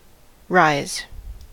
rise: Wikimedia Commons US English Pronunciations
En-us-rise.WAV